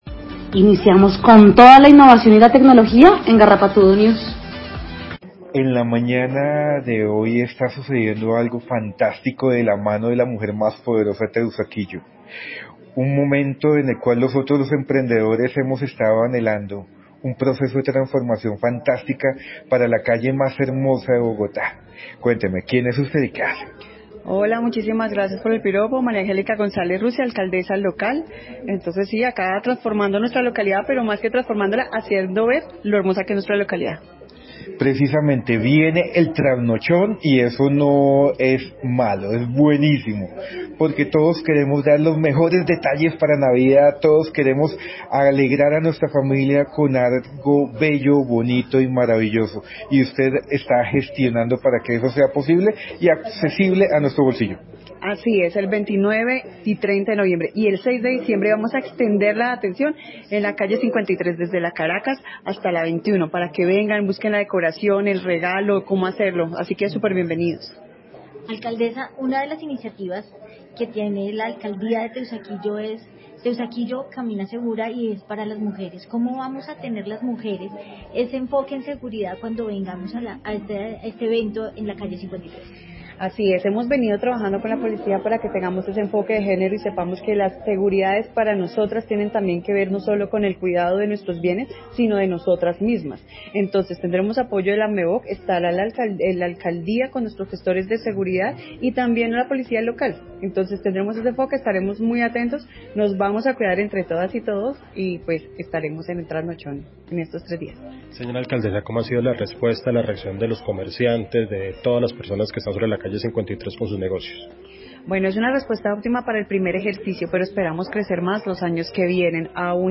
A la alcaldesa local de Teusaquillo María Angélica González Russi